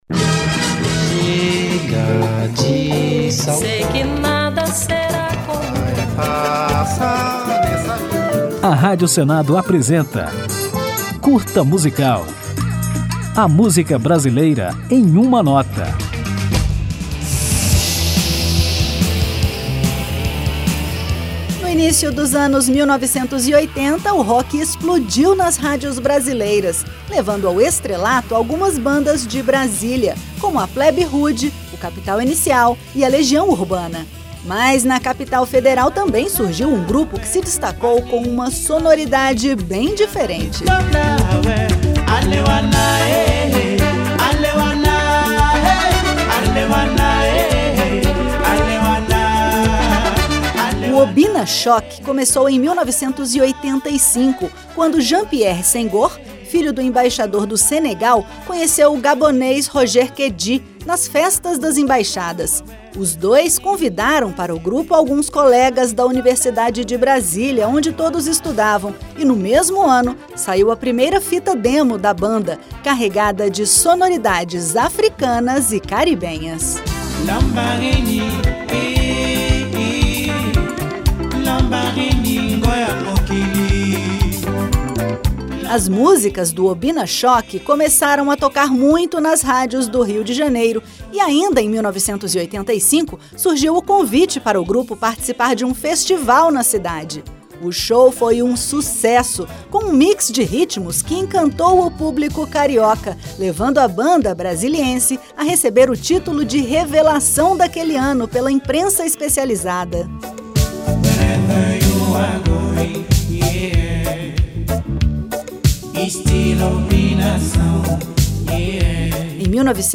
O Curta Musical apresenta a história do Obina Shok, uma banda brasiliense marcada pela fusão de sonoridades afro-caribenhas, que foi formada em 1985, no auge do rock nacional. Trazendo integrantes da África, do Suriname e do Brasil, o grupo atingiu um sucesso meteórico, porém breve, puxado pela música Vida, com participações especiais de Gal Costa e Gilberto Gil, um hit que você vai conferir no final do programa.